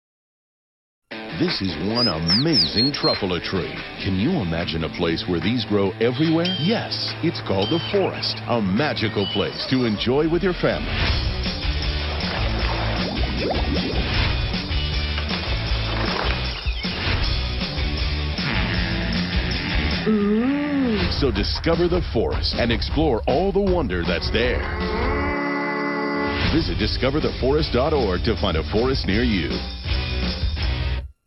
The Lorax - PSA